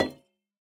Minecraft Version Minecraft Version 1.21.5 Latest Release | Latest Snapshot 1.21.5 / assets / minecraft / sounds / block / copper_bulb / place4.ogg Compare With Compare With Latest Release | Latest Snapshot